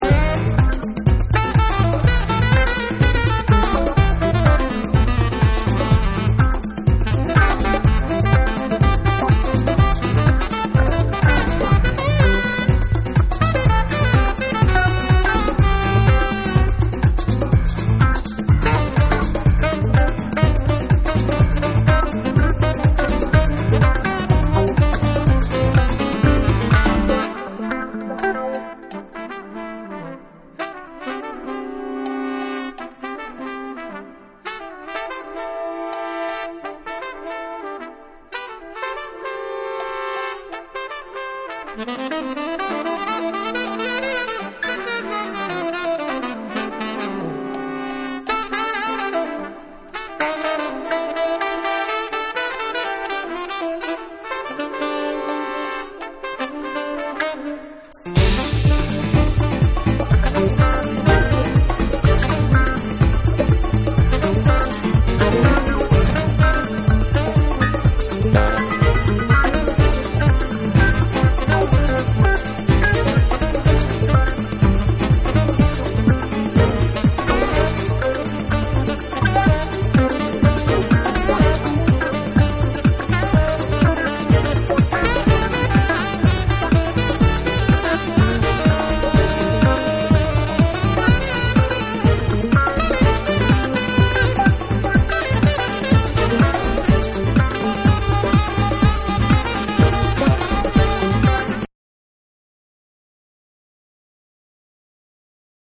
キャッチーなメロディーが展開したUKソウルを披露した3（SAMPLE1）。
TECHNO & HOUSE / ALL 500YEN